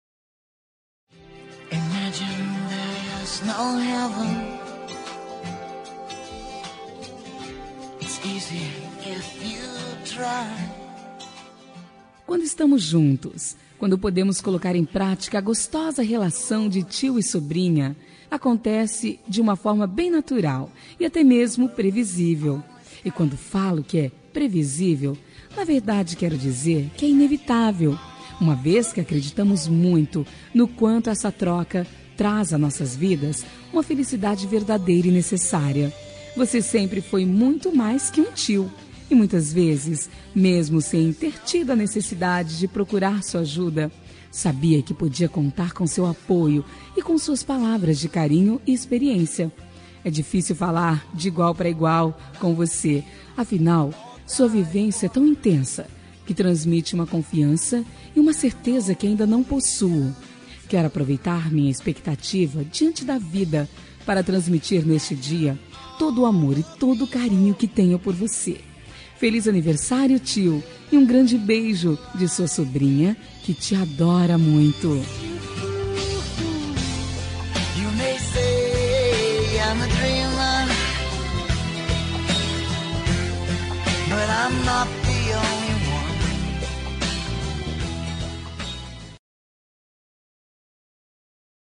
Aniversário de Tio – Voz Feminina – Cód: 927